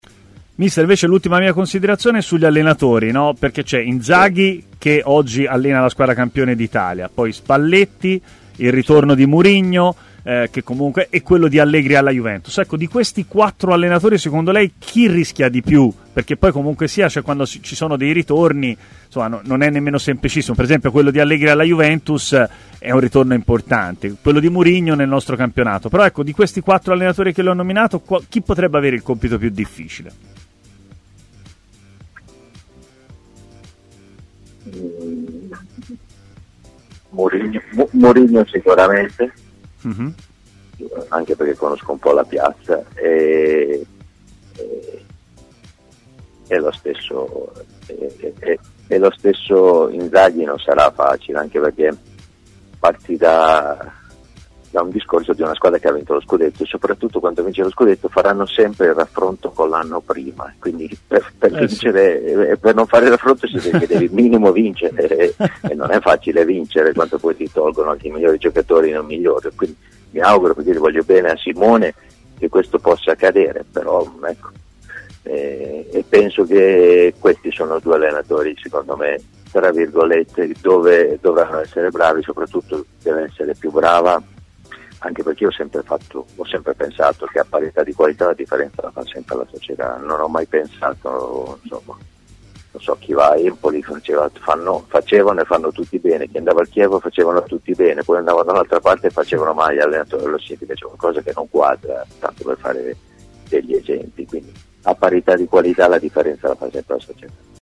Non ha dubbi Delio Rossi, che nel suo intervento odierno ai microfoni di TMW Radio ha indicato lo Special One come tecnico "più a rischio", considerando solo i pesi massimi del campionato italiano: "Ma anche Inzaghi - ha poi aggiunto l'ex tecnico biancoceleste -, dico che per lui all’Inter non sarà facile.